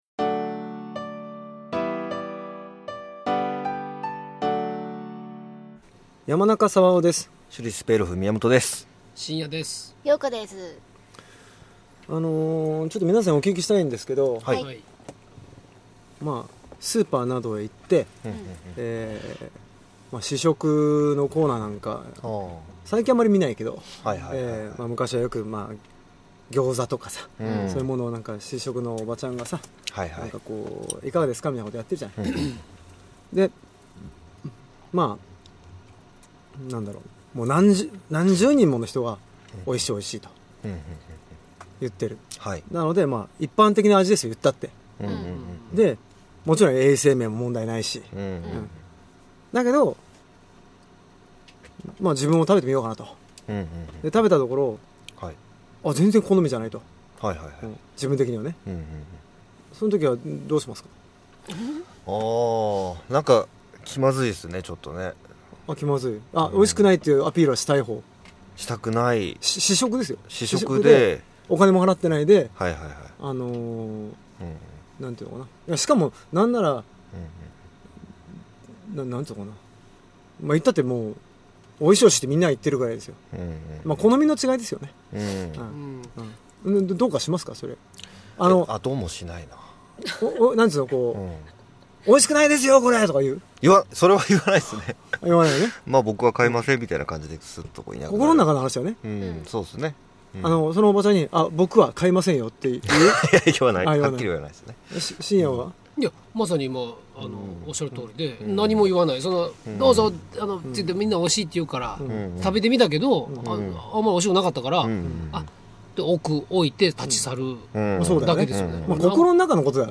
Cast：山中さわお